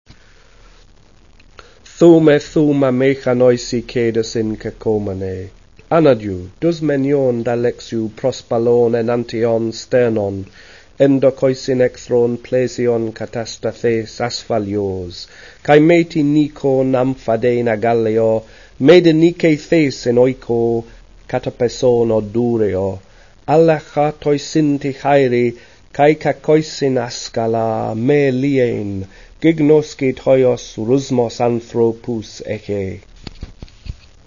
spoken version